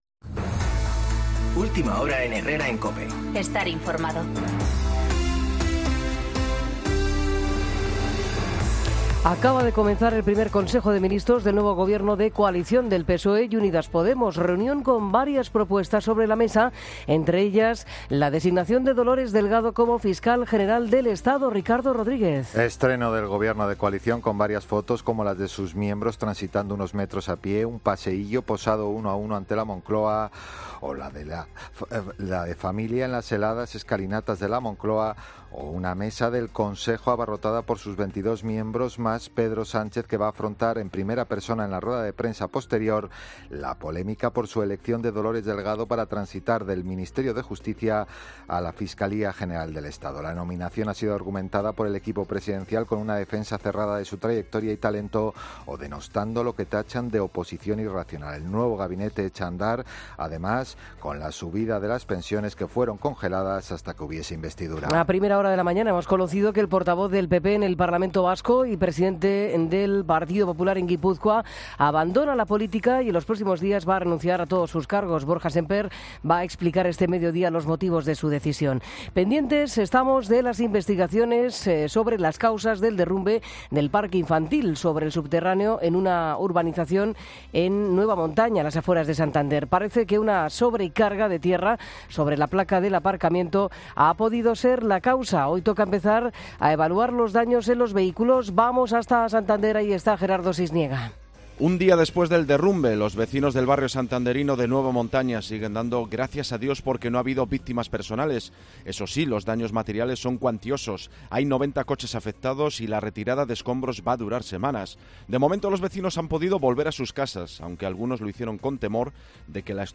Boletín de noticias COPE del 14 de enero de 2020 a las 10.00 horas